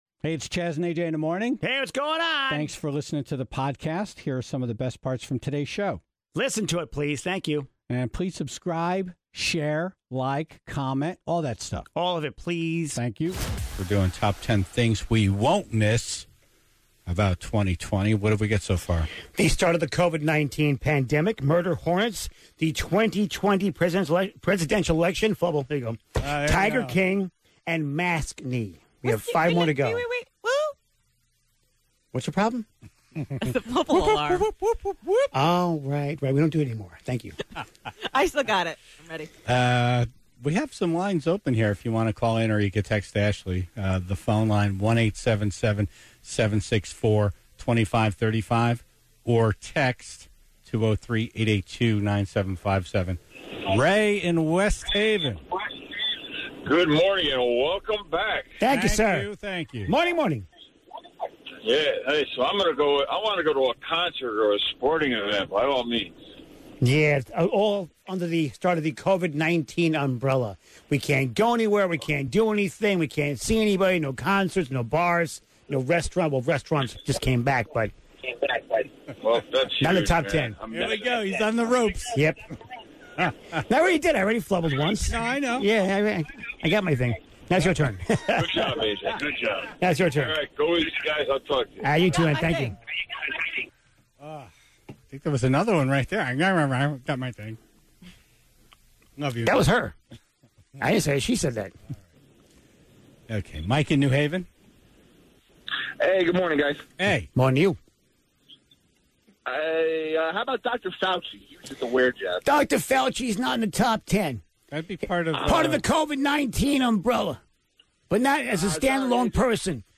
(25:49) Rock photographer Bob Gruen has so many incredible stories about the bands and acts he's had a chance to meet.